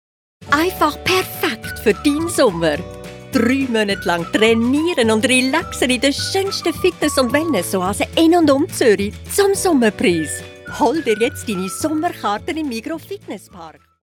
Werbung Schweizerdeutsch (AG)